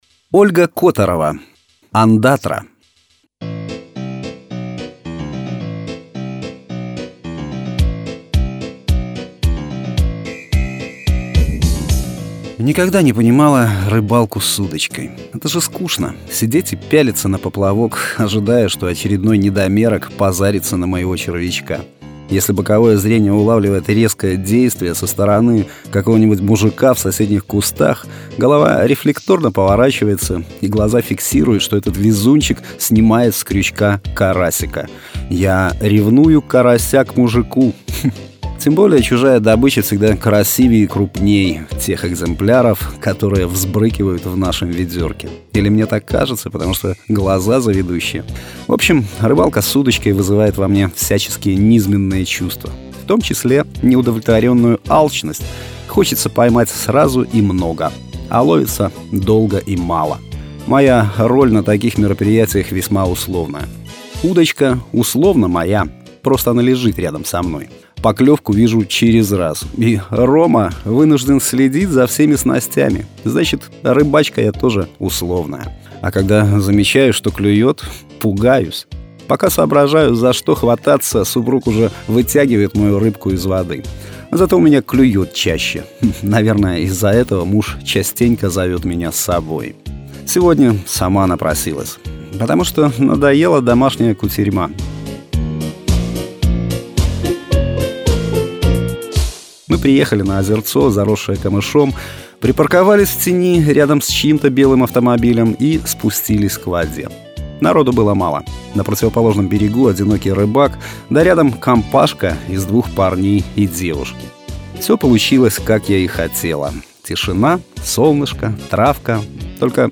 Аудиорассказ
Жанр: Современная короткая проза